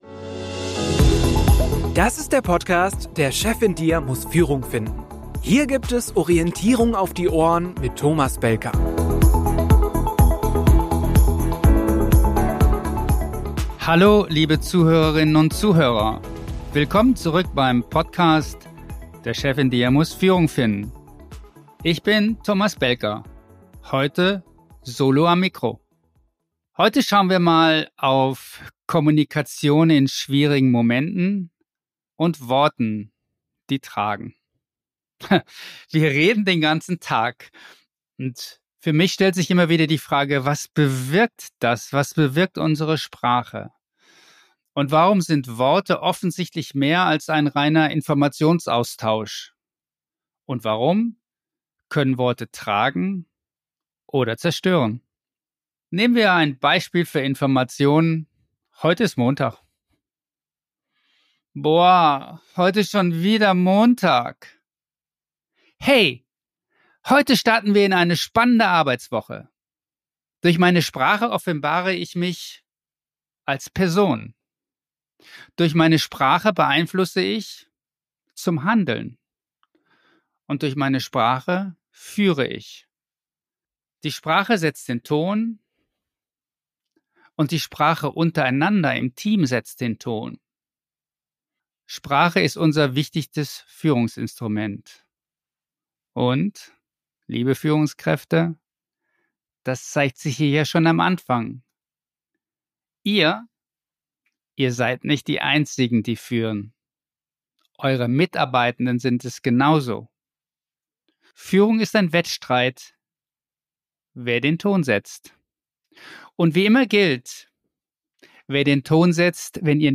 In dieser Solo-Episode geht’s um das, was in schwierigen Momenten wirklich trägt: das Gespräch. Nicht die Einbahnkommunikation, sondern ein Dialog, der Verstehen, Mitgestaltung und Bindung erzeugt.